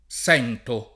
sentire v.; sento [